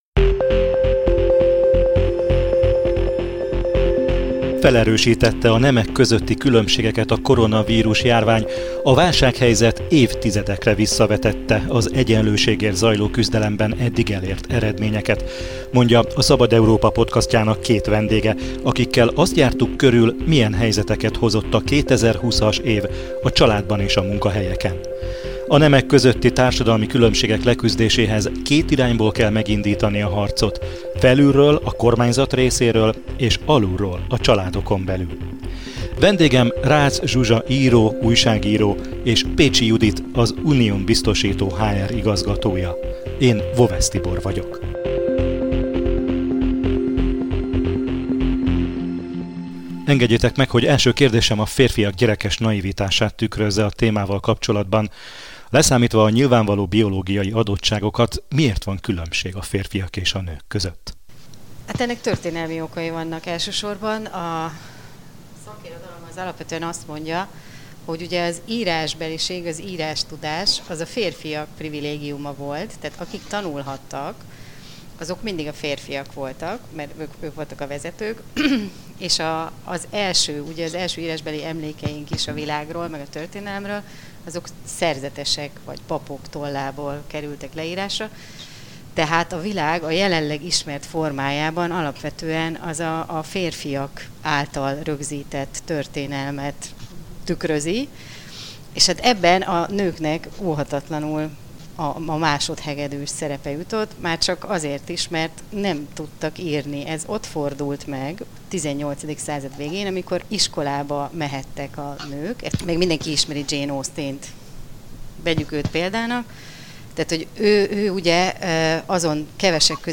A női egyenjogúság a családügyi miniszter vihart kavart videóüzenetével került ismét a figyelem középpontjába. Ráadásul a nemek közti különbségeket felerősítette a koronavírus-járvány – mondja két vendégünk.